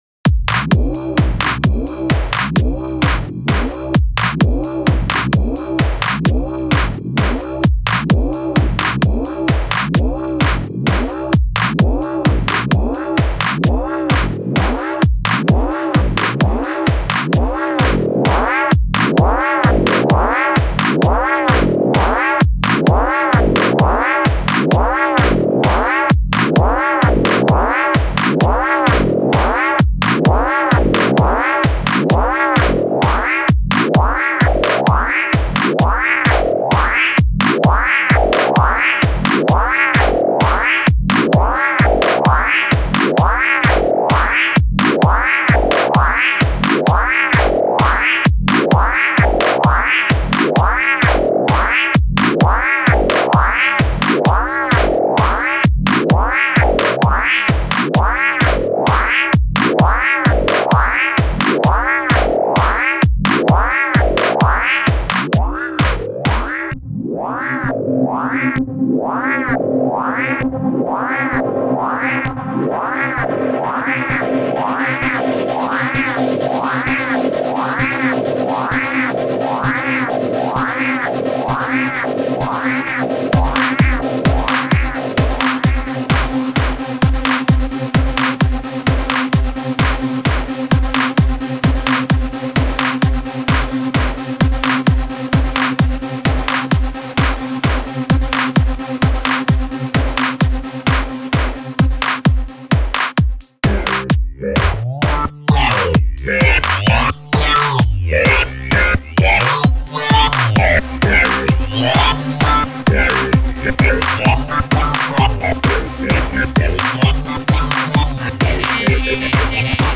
хм, сидел делал в ушах, было норм, все детали хорошо слышались, сейчас первый раз послущал с колонок, чет совсем тихо все.
ну я ващет семплы не юзал, а так да, мне прост вобла в начале понравилась и решил на ней весь трек сделать.